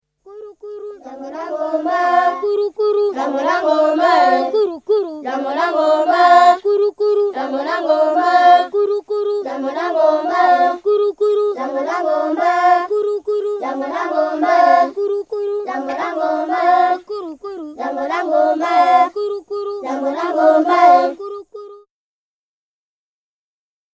Ονομάζεται «Κούρου κούρου» και τραγουδιέται από τους Πυγμαίους Άκα.